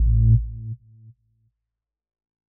Power Off.wav